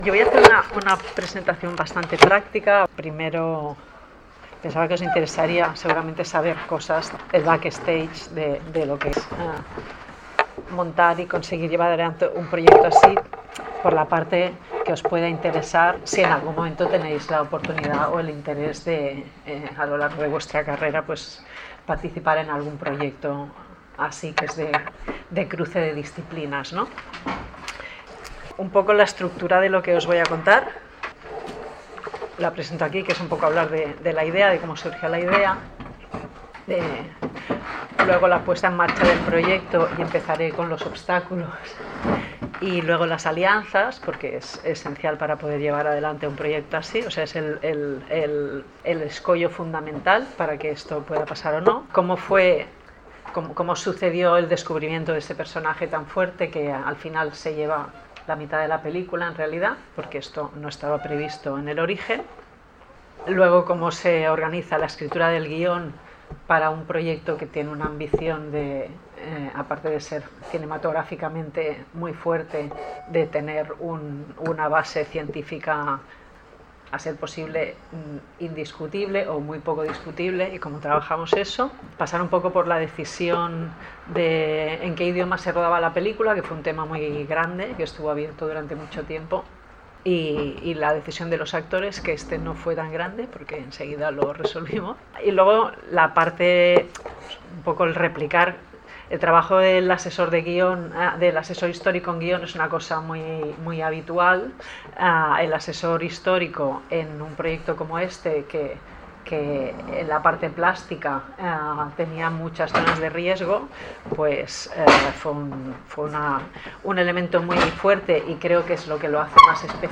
Continuó evocando detalles de la elaboración del documental, de su escritura así como del rodaje. Terminó hablando de su impacto en Granada, en España y también en el mundo. Podcast audio_chapitre audio_chapitre Introducción 00:00 1. Obstáculos y alianzas 03:23 2. El guión y el personaje principal 11:10 La construcción del personaje de Ibn al-Khatib 12:55 La escritura del guión y el papel de los asesores históricos 16:30 3. El rodaje 20:01 Conclusión: el impacto del documental 30:17